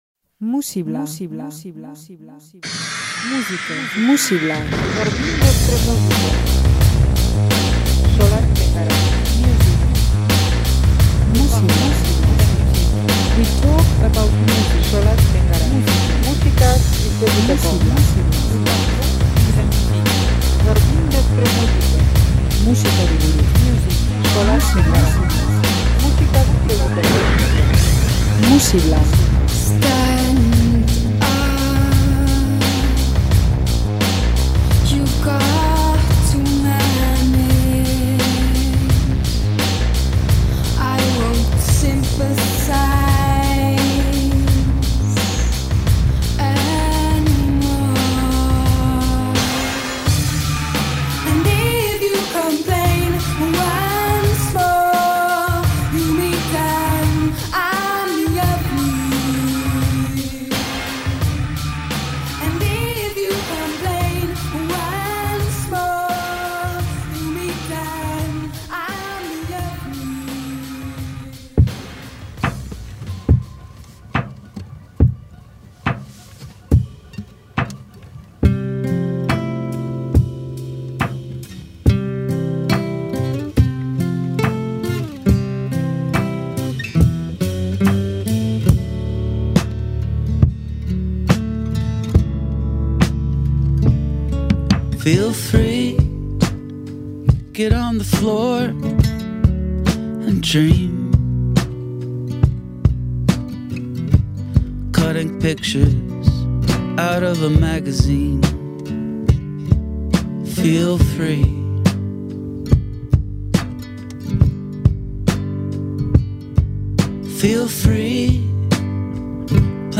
folk eta rock alternatiboaren barrenean bidaiatuko dugu
elkarrekin giro atmosferiko eta errealista eskaintzen dute.
iluntasun dotorean eta post-punk giroetan sartuko gara